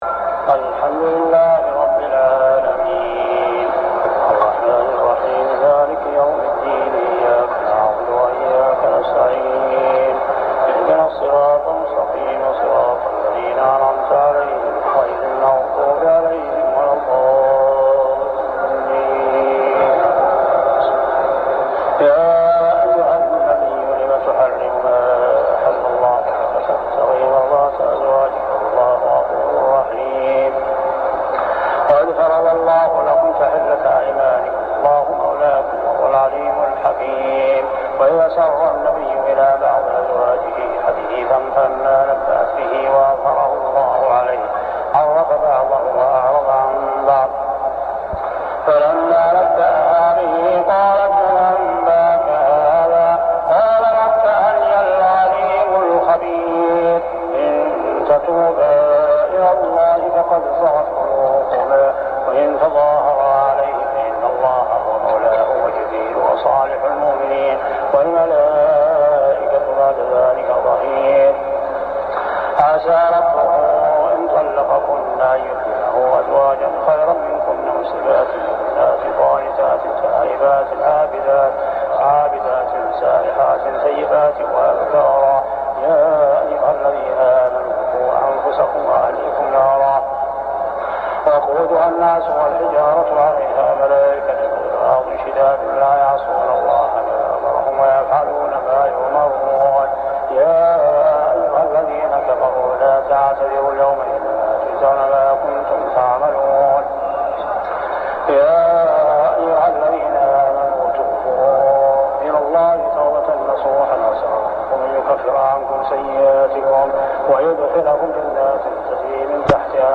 صلاة التراويح عام 1399هـ من سورة التحريم حتى سورة المعارج | Tarawih Prayer From Surah At-Tahrim to Al-ma'arij > تراويح الحرم المكي عام 1399 🕋 > التراويح - تلاوات الحرمين